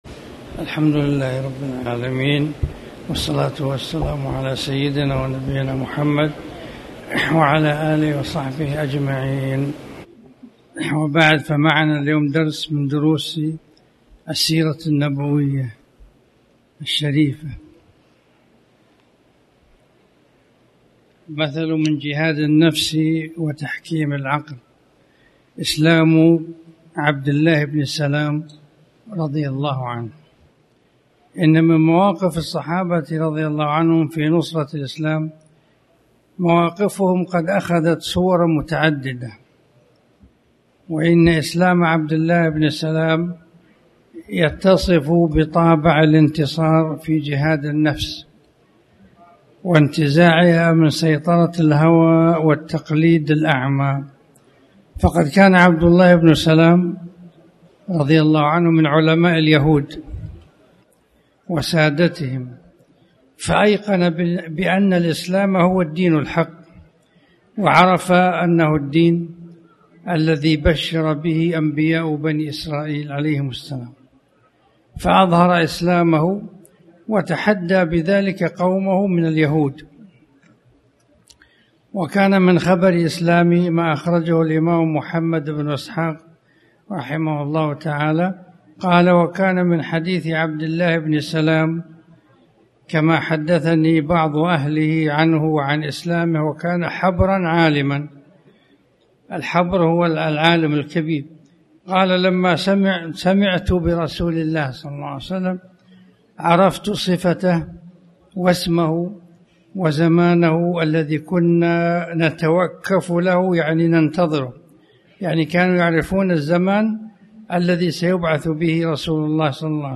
تاريخ النشر ١١ محرم ١٤٣٩ هـ المكان: المسجد الحرام الشيخ